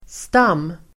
Uttal: [²st'am:-]